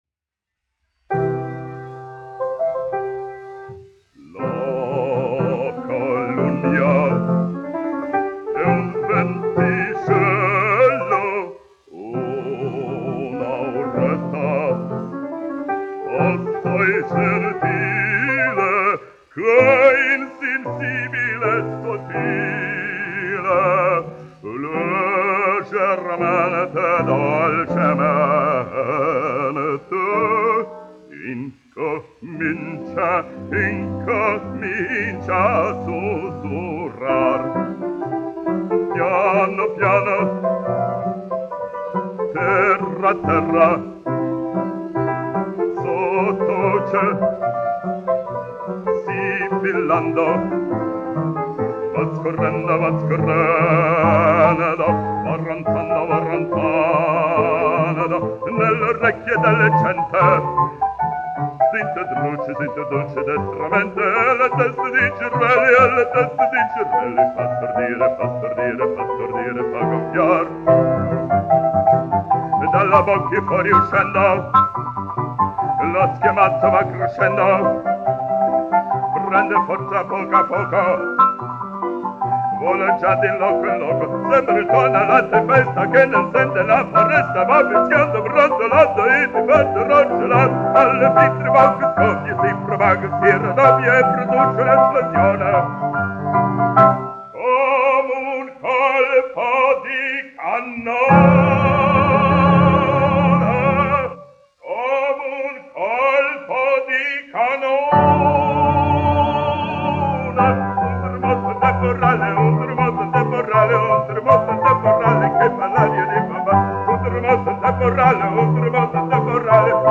1 skpl. : analogs, 78 apgr/min, mono ; 25 cm
Operas--Fragmenti, aranžēti
Skaņuplate